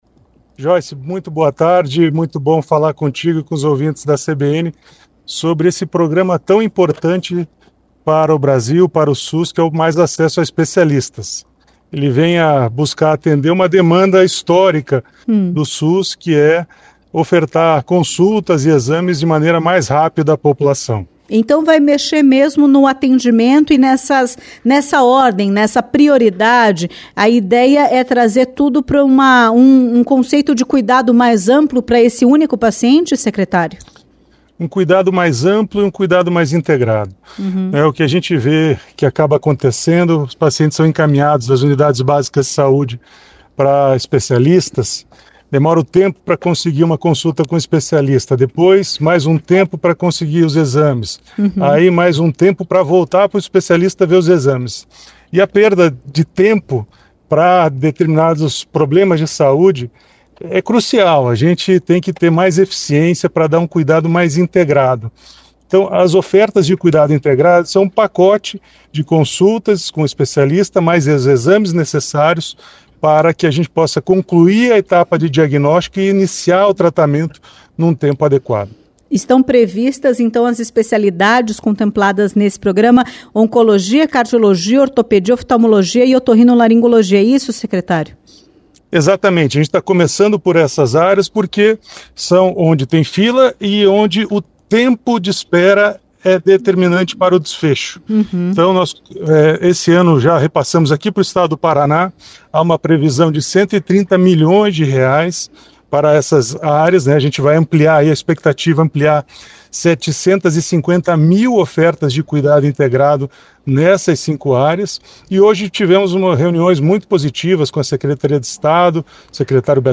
Em entrevista à CBN Curitiba, o secretário de Atenção Especializada à Saúde do Ministério do Saúde, Adriano Massuda, detalhou o programa e o investimento no Paraná.